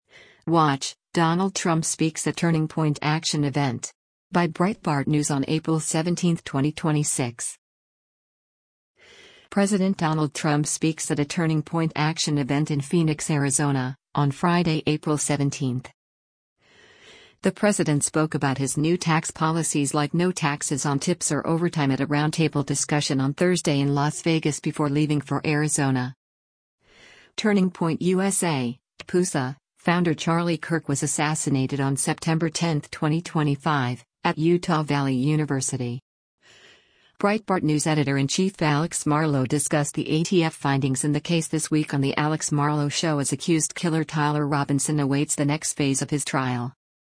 President Donald Trump speaks at a Turning Point Action event in Phoenix, Arizona, on Friday, April 17.